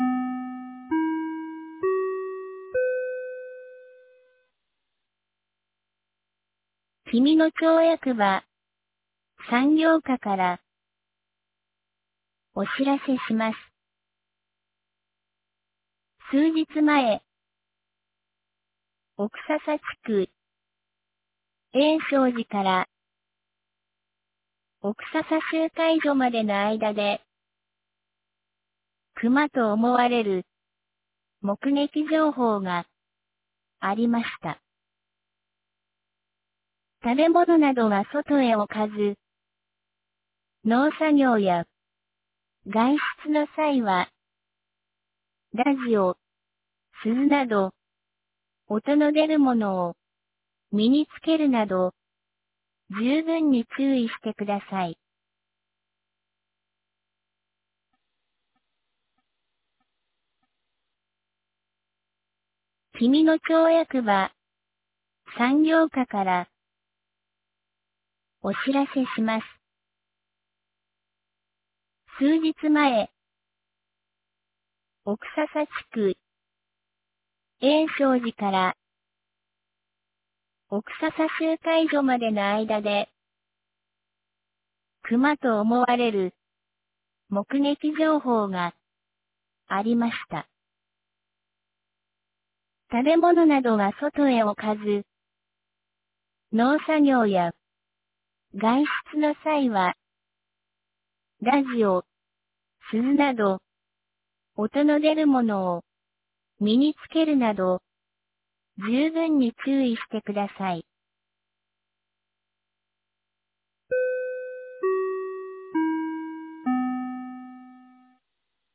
2022年05月17日 11時39分に、紀美野町より小川地区へ放送がありました。